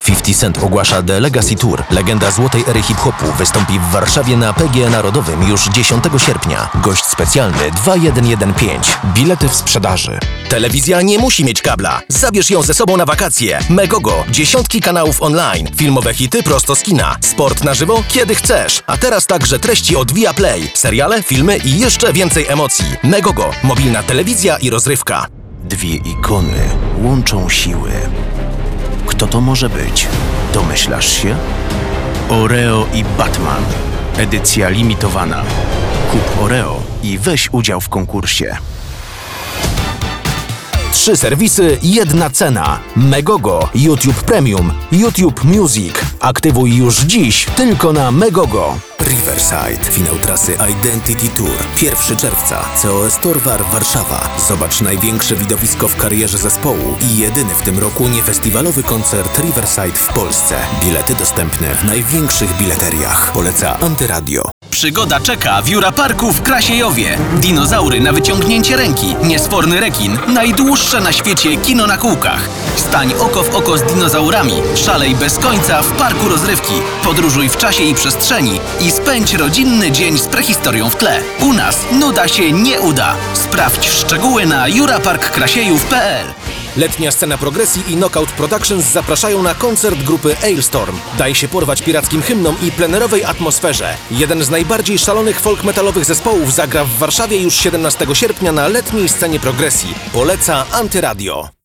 DEMO REKLAMOWE (kompilacja):